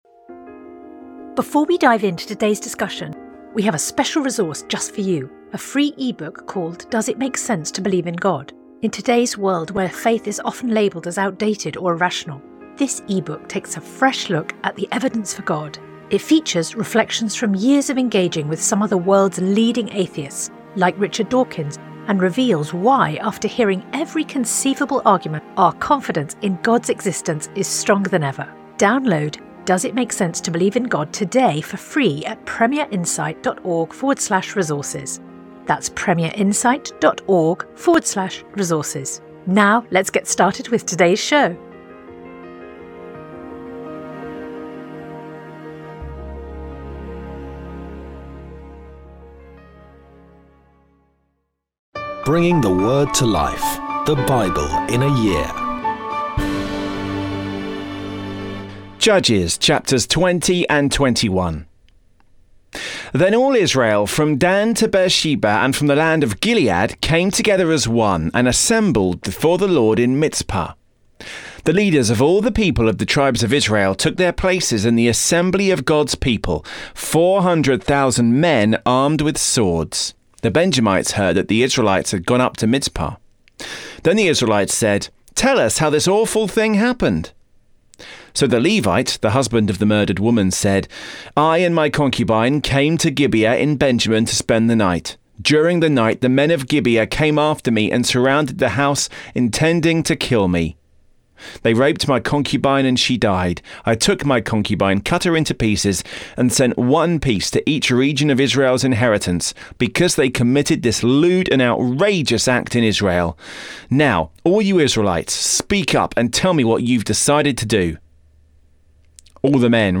Today's readings come from Judges 20-21; 2 Peter 3